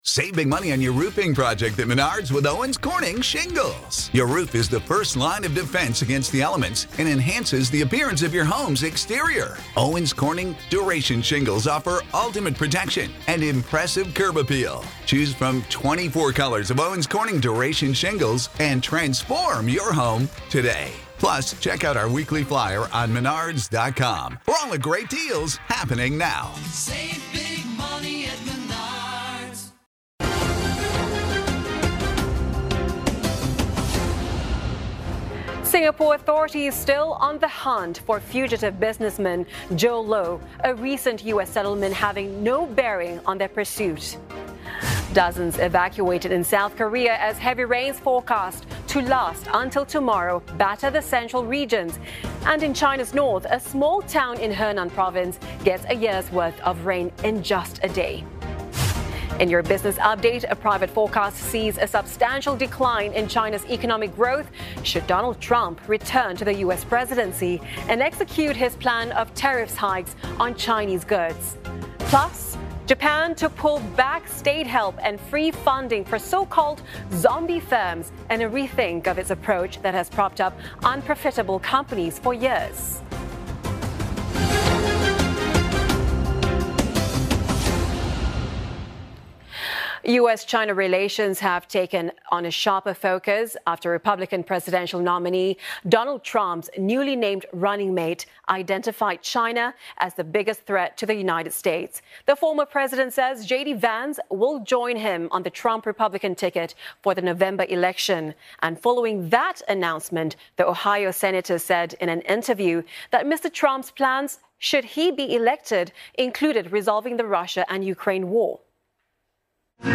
live report from Milwaukee for pan-Asian TV network CNA